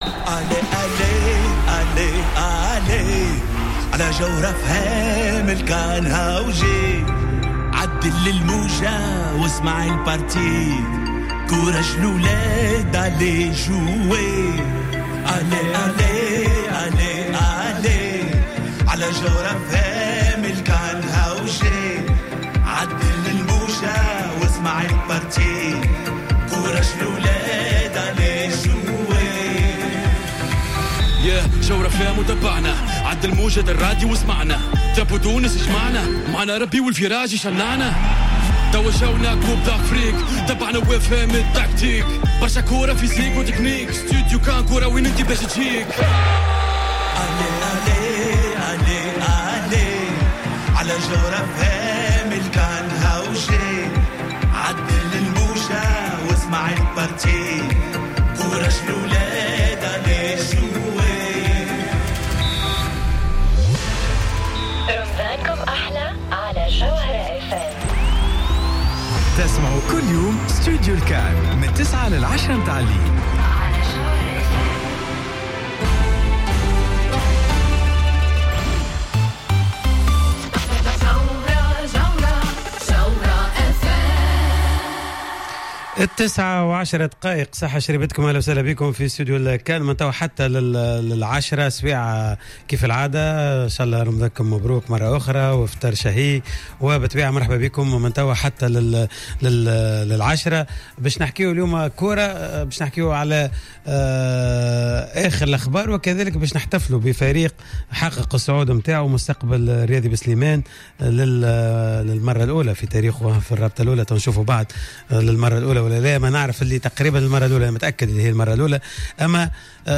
ضيفا على راديو جوهرة افم في حصة إستوديو الكان...